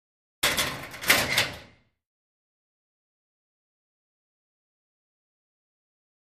Locker Room; Metal Locker Door Close.